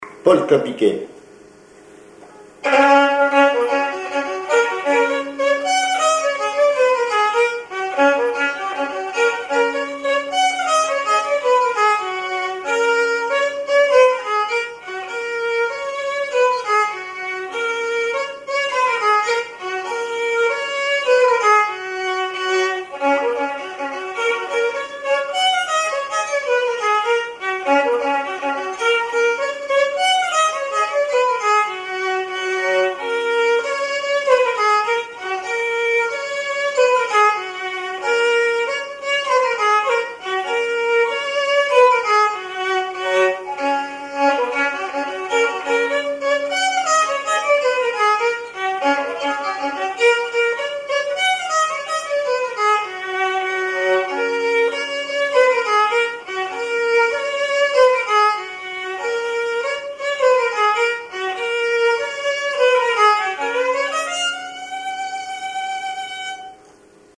violoneux, violon
danse : polka piquée
Pièce musicale inédite